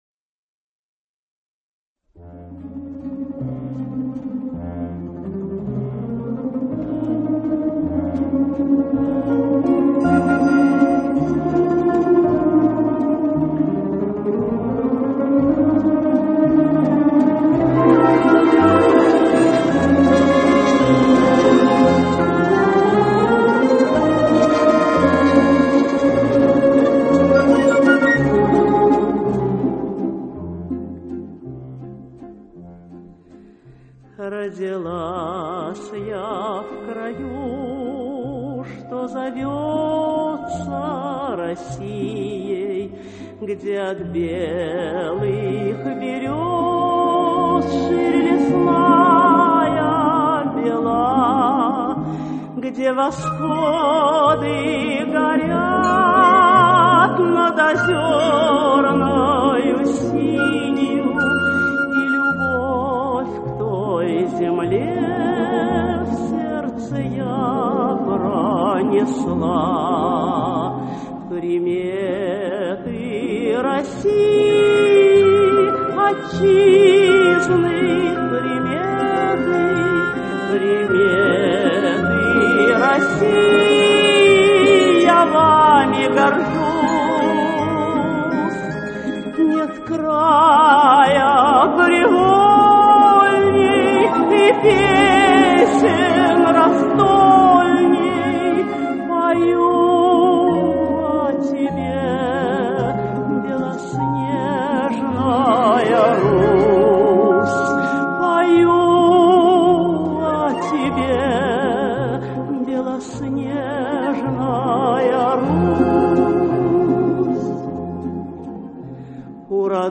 Очень красивая мелодичная гордая песня.